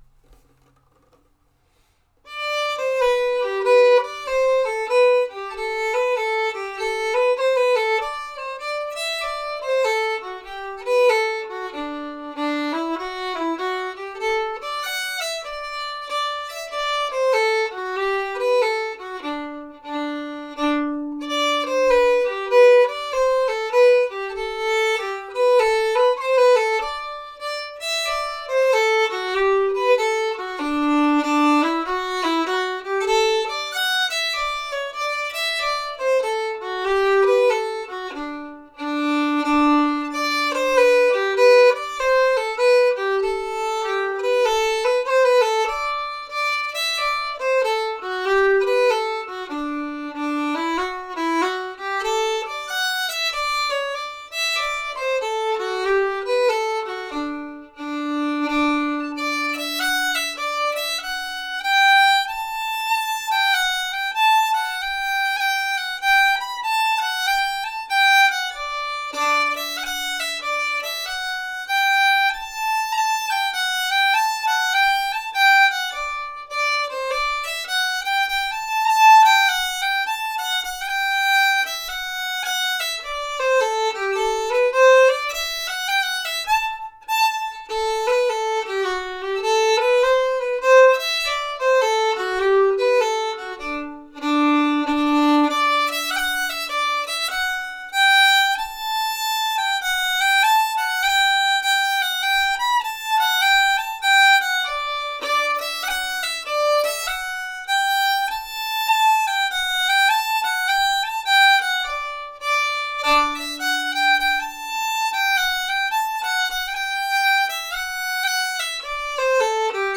The-Blackbird-Slow.m4a